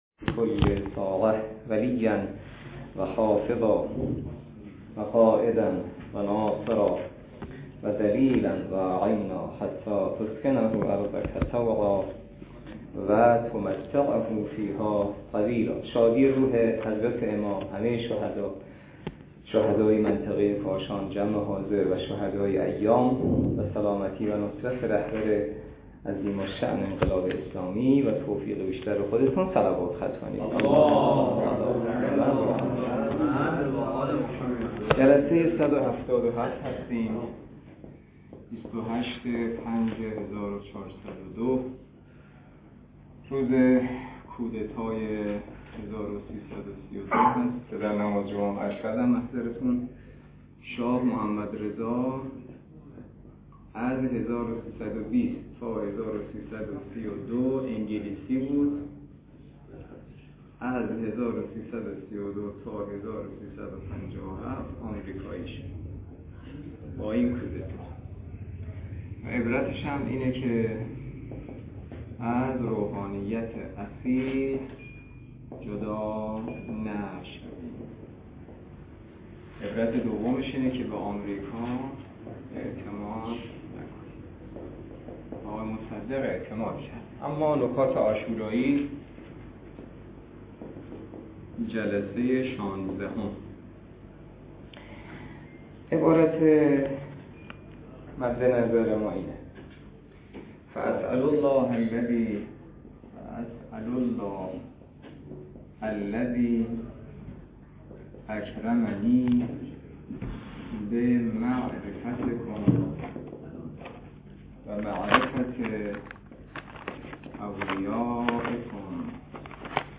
درس فقه الاجاره نماینده مقام معظم رهبری در منطقه و امام جمعه کاشان - جلسه صد و هفتاد و هفت